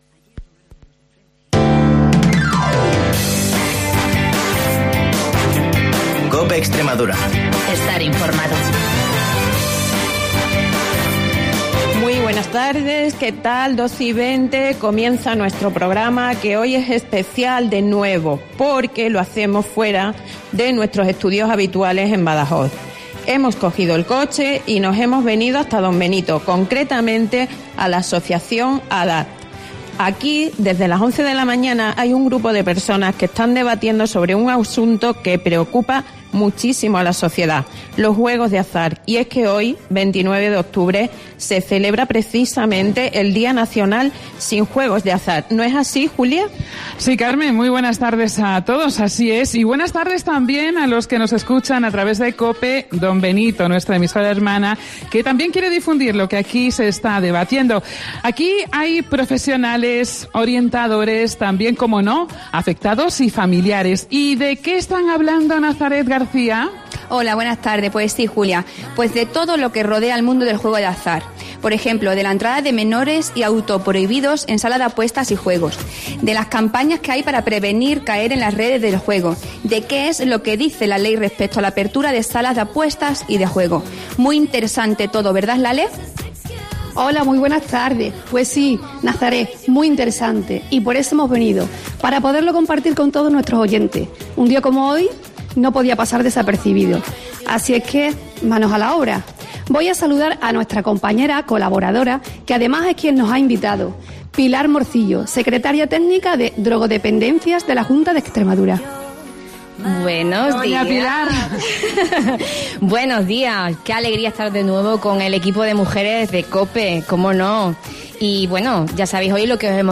El equipo de Herrera en COPE Badajoz desplazado hoy a Don Benito
Hoy , en Herrera en COPE Badajoz, hemos querido ser testigos de lo que "se cuece" en una mesa de debate sobre los juegos de azar. Se ha celebrado en las instalaciones de ADAT, en Don Benito, y han participado orientadores, profesionales y personas cercanas de uno modo u otro al mundo del juego de azar.